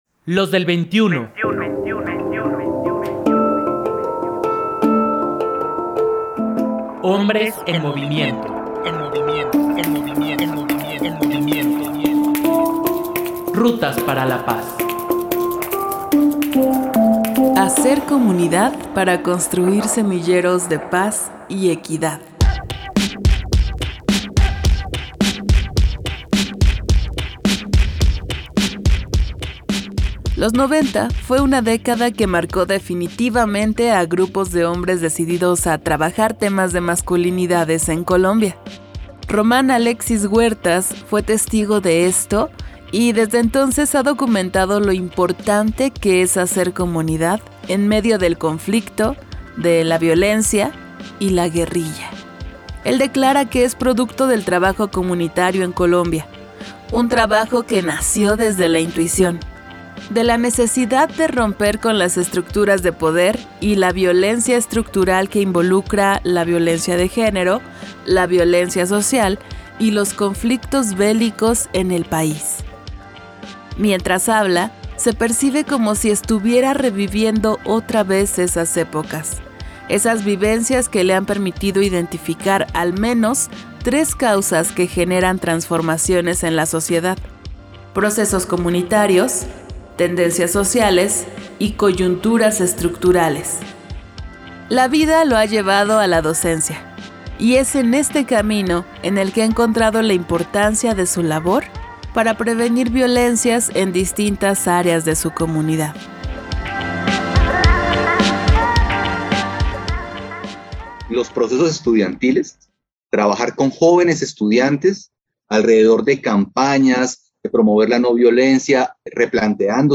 Mientras habla, se percibe como si estuviera reviviendo otra vez esas épocas, esas vivencias que le han permitido identificar al menos tres causas que generan transformaciones en la sociedad: procesos comunitarios, tendencias sociales y coyunturas estructurales.